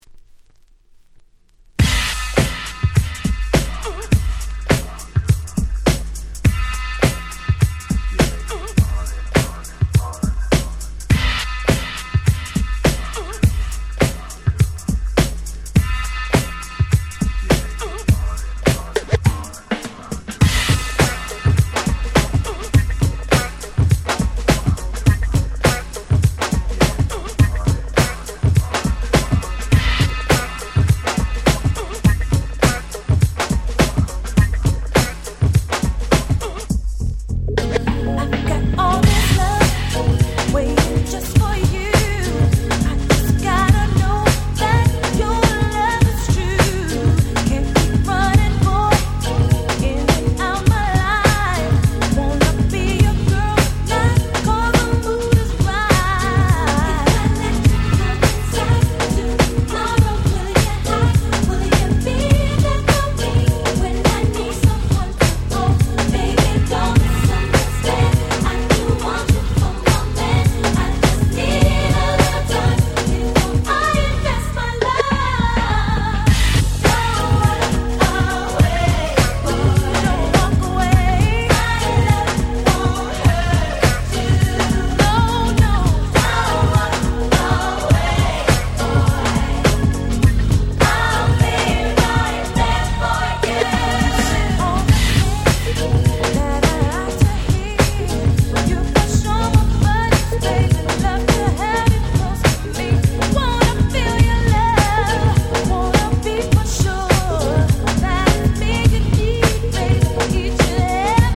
ウィキードミックス 90's Boom Bap ブーンバップ R&B 勝手にRemix 勝手にリミックス ミックス物